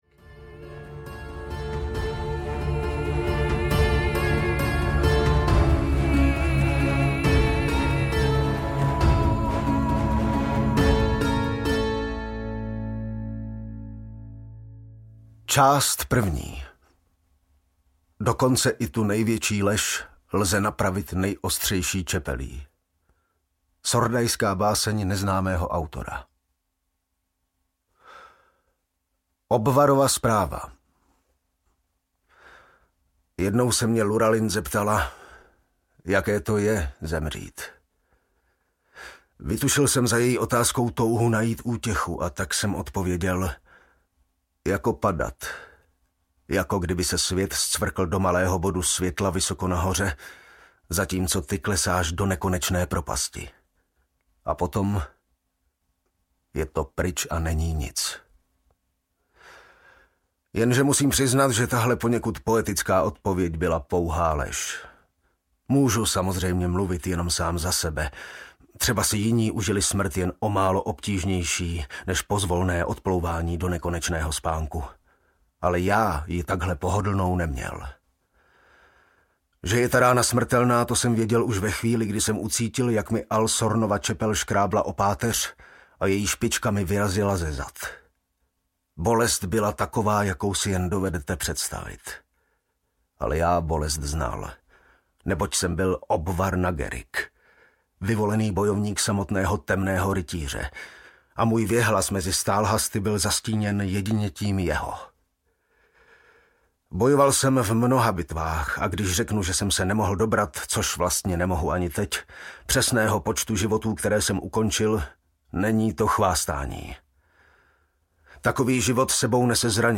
Černá píseň audiokniha
Ukázka z knihy